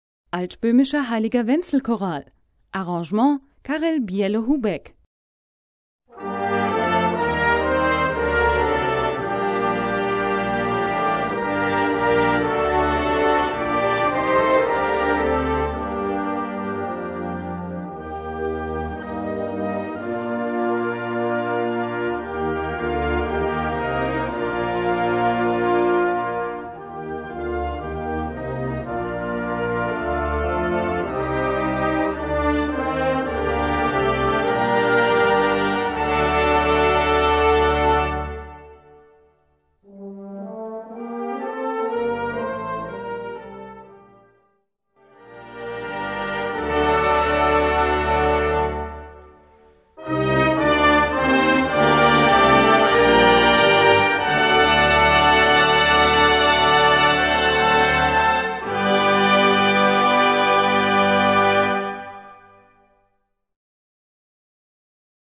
Gattung: Choral
Besetzung: Blasorchester